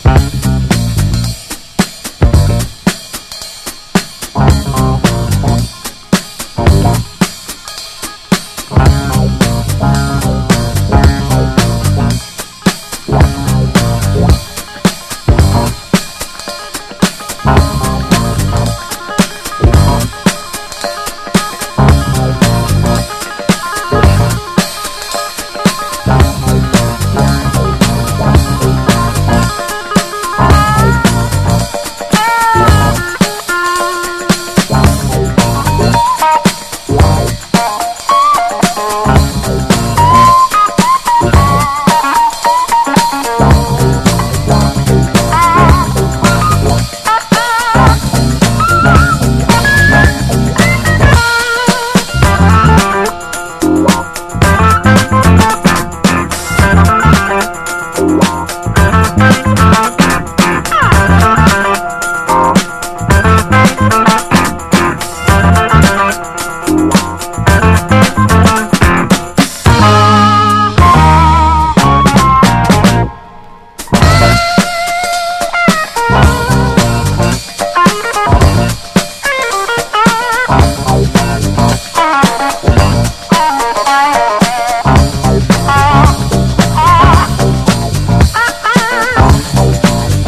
ノーザン・ソウル好きにもオススメの洗練されたサザン・ソウル・シンガー！